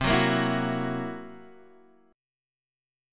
C7b5 chord {x 3 4 3 5 2} chord